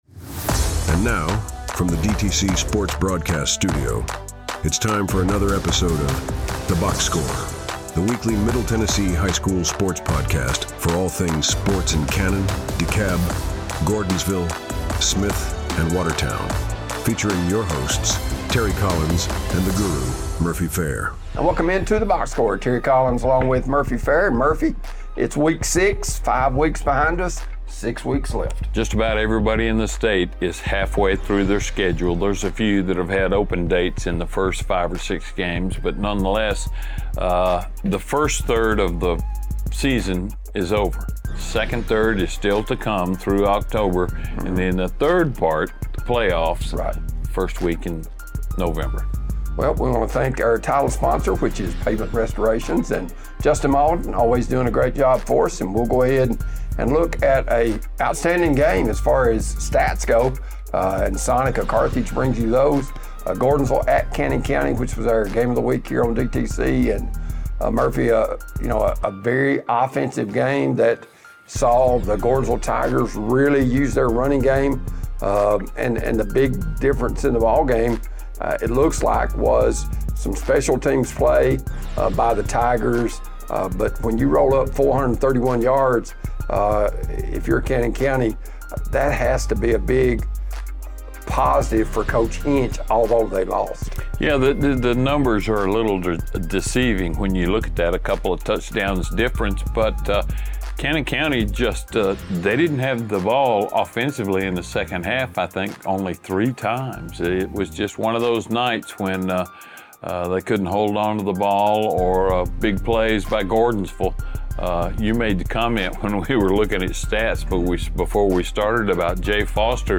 weekly sports talk show
guest interviews